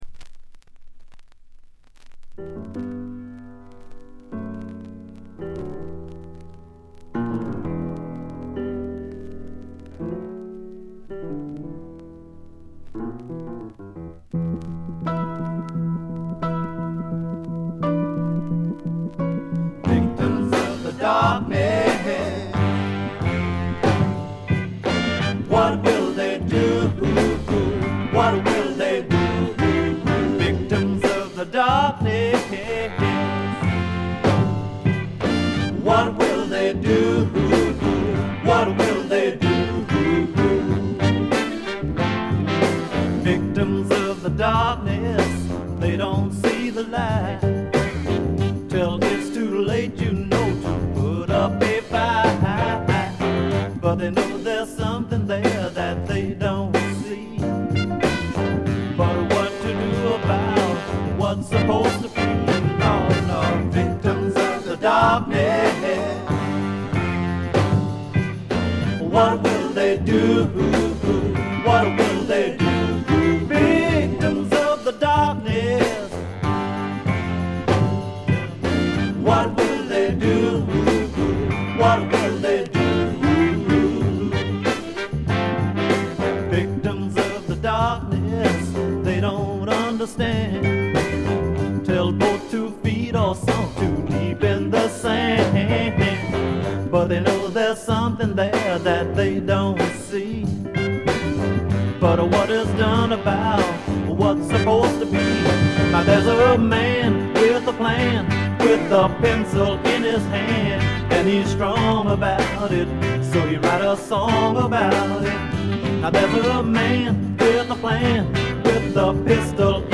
ところどころでチリプチ（特にA1冒頭や曲間の静音部）。鑑賞を妨げるようなノイズはありません。
ニューオーリンズ・ファンクを代表する名盤中の名盤。
セカンドライン・ビート、ニューソウル的なメロウネス、何よりも腰に来るアルバムです。
試聴曲は現品からの取り込み音源です。